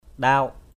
/ɗaʊ:ʔ/ 1. (t.) bằng, phẳng = plat, plan, uni. tanâh ndap tnH QP đất bằng = terrain plat. papan ndap ppN QP bàn phẳng = table à surface unie. 2....